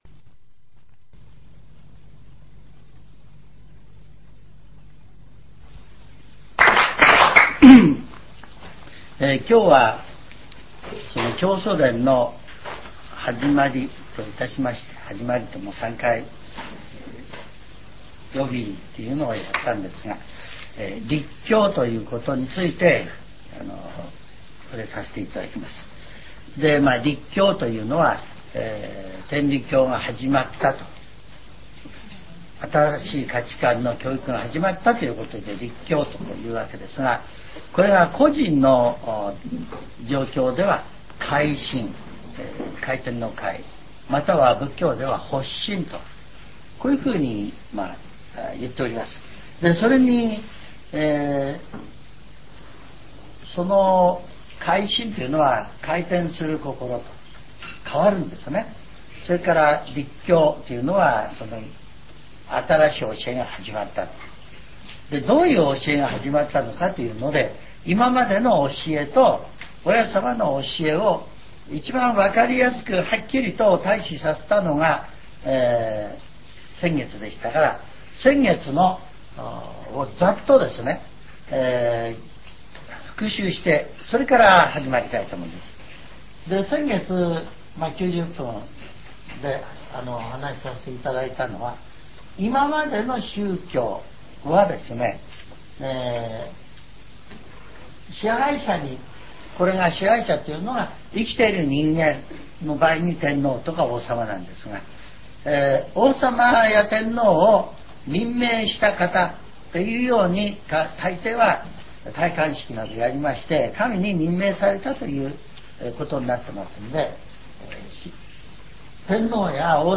全70曲中10曲目 ジャンル: Speech